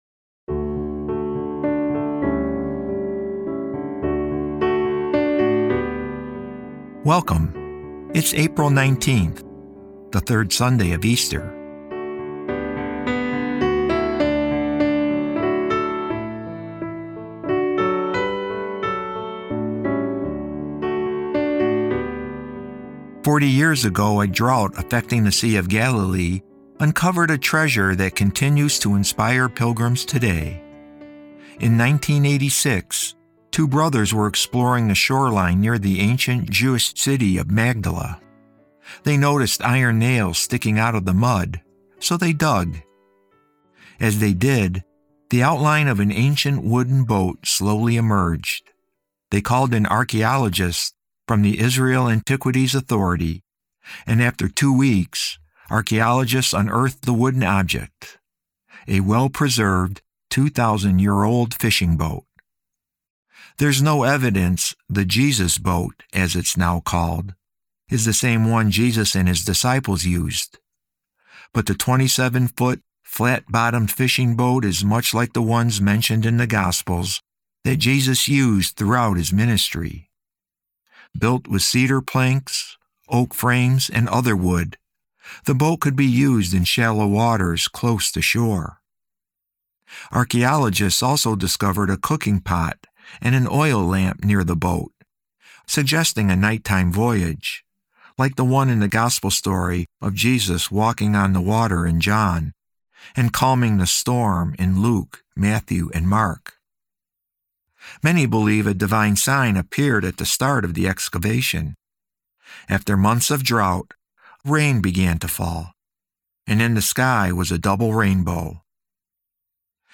Today's episode of Sundays with Bishop Ken is a reading from The Little White Book: Easter 2026.